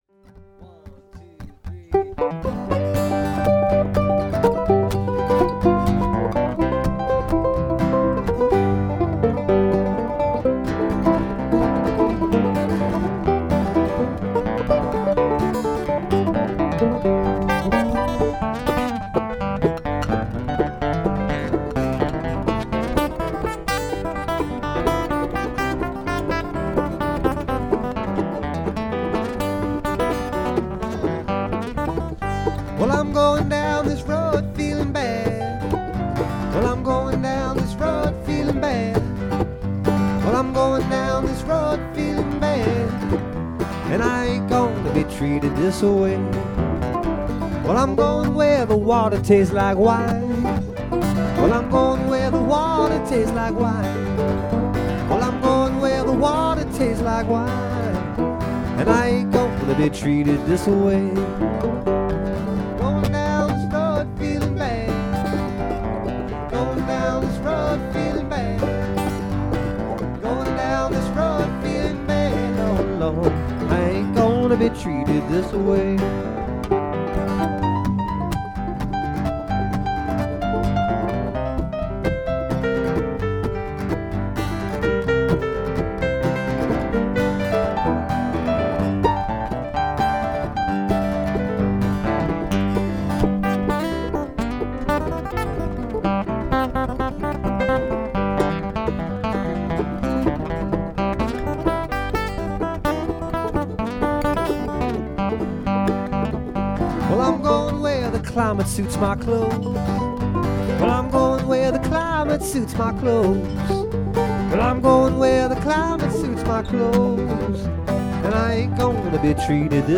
banjo, mandolin, fiddle & vocals
guitar & vocals
piano & vocals